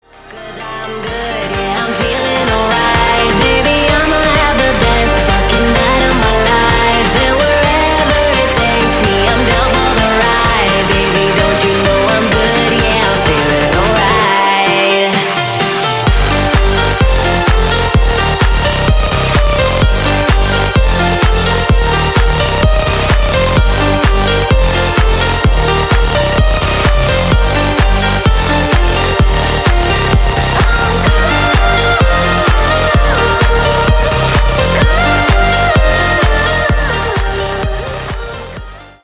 électronique